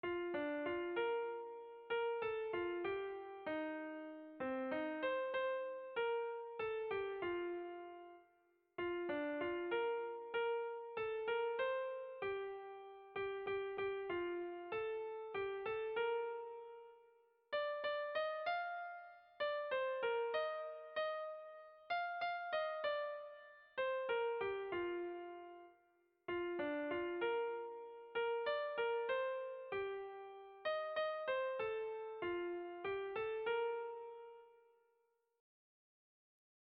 Kontakizunezkoa
Bertsolaria
AB1DB2